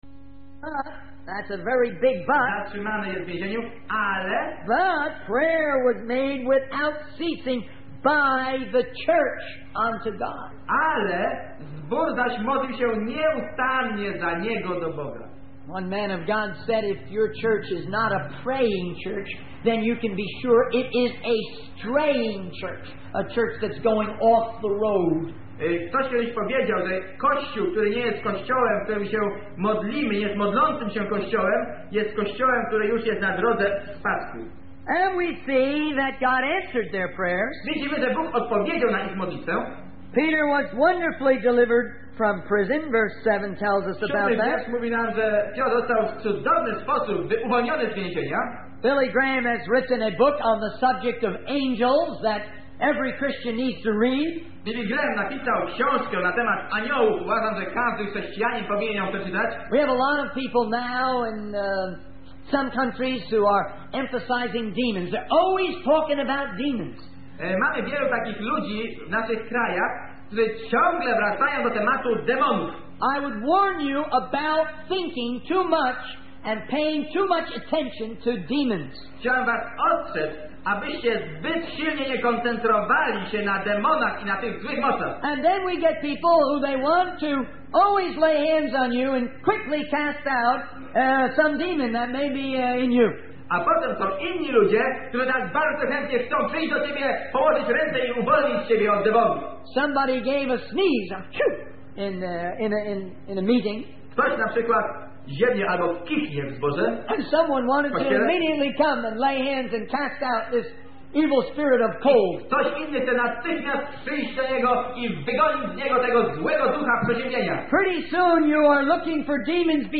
In this sermon, the speaker emphasizes the importance of spending time in the Word of God and meditating on Scripture. He cautions against mistaking every thought that comes to mind while reading the Bible as a special revelation from God. The speaker also highlights the need for unity among believers and encourages them to plan their days and be organized in their work.